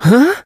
sandy_hurt_vo_01.ogg